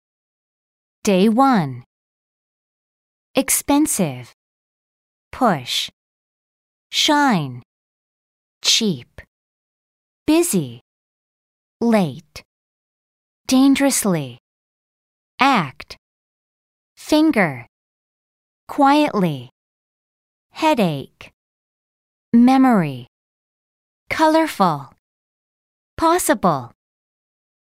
QR코드를 찍으면 이런 화면이 뜨면서 단어의 발음을 원어민 음성으로 들을 수 있어요.
mp3는 반복 없이 단어를 쭉 읽어주기 때문에 단어를 들으면서 쓰기엔 조금 빠르게 느껴져요